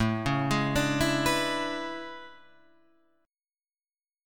A Major 11th